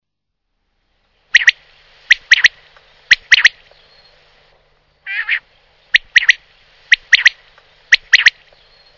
quaglia.wav